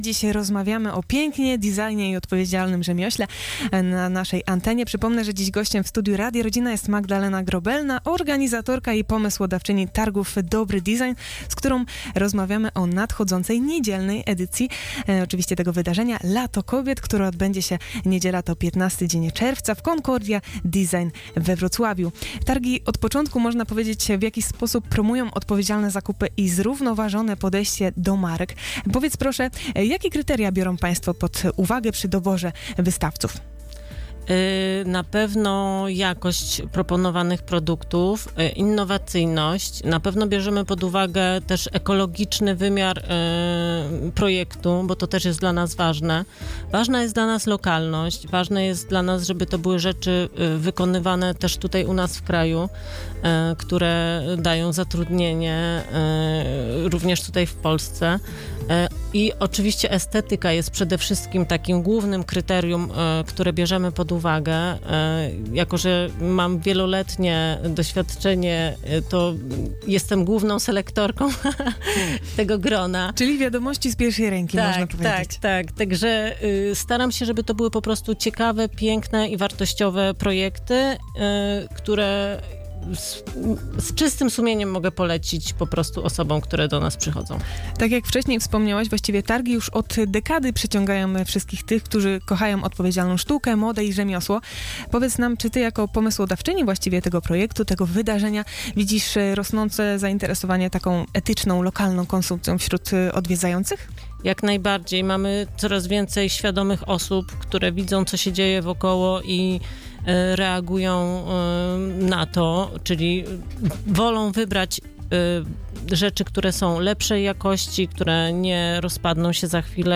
Pełnej rozmowy